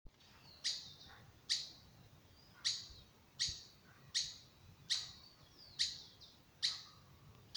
Myiodynastes maculatus solitarius
English Name: Streaked Flycatcher
Life Stage: Adult
Condition: Wild
Certainty: Photographed, Recorded vocal